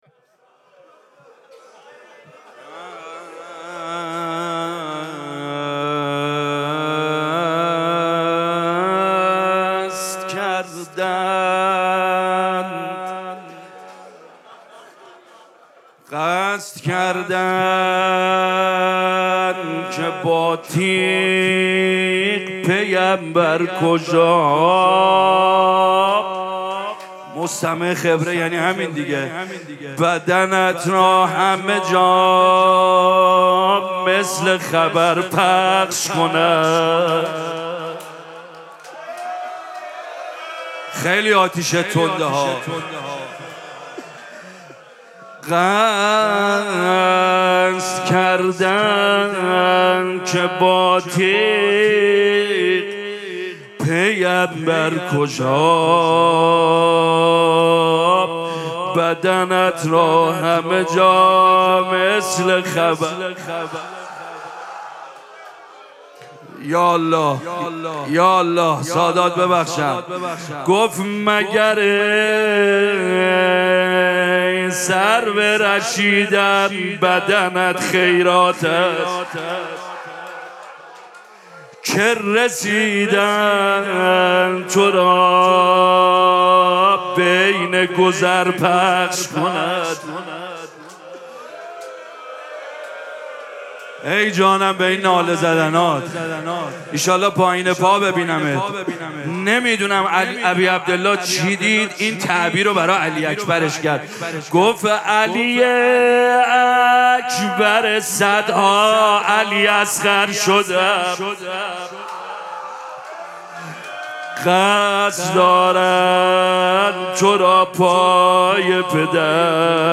مراسم مناجات شب بیستم ماه مبارک رمضان
روضه